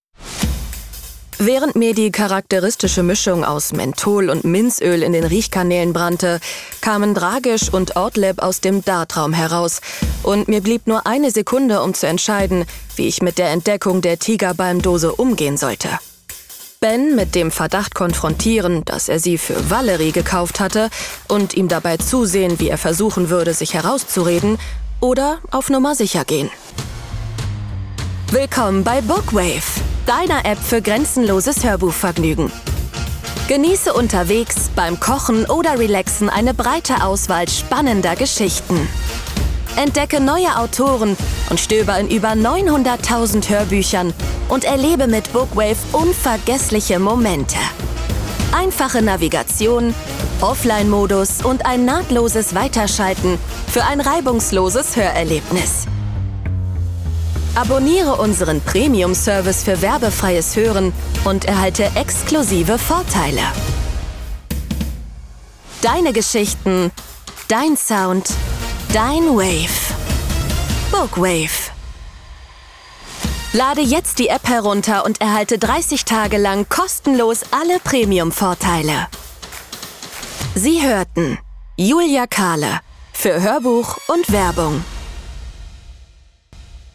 Natürlich, Warm, Freundlich, Sanft
Unternehmensvideo